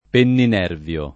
vai all'elenco alfabetico delle voci ingrandisci il carattere 100% rimpicciolisci il carattere stampa invia tramite posta elettronica codividi su Facebook penninervio [ pennin $ rv L o ] agg. (bot.); pl. m. -vi (raro, alla lat., -vii )